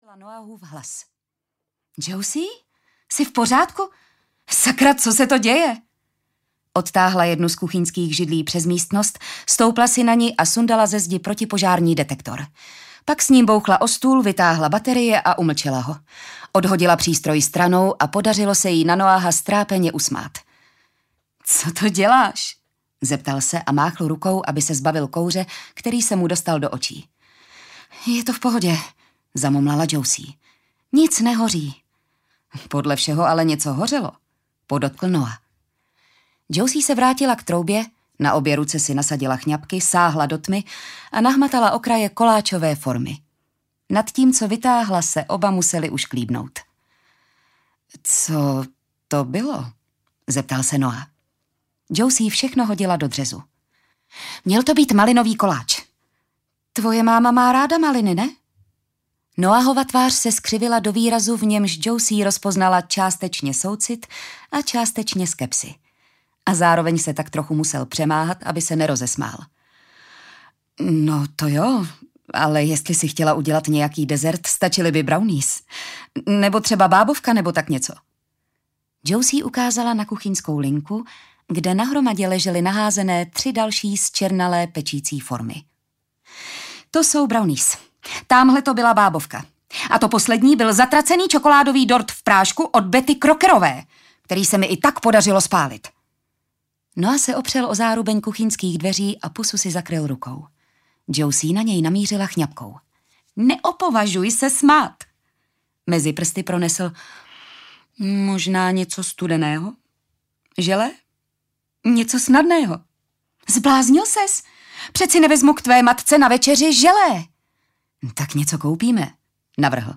Pohřbené kosti audiokniha
Ukázka z knihy
pohrbene-kosti-audiokniha